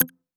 check-off.wav